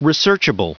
Prononciation du mot researchable en anglais (fichier audio)
Prononciation du mot : researchable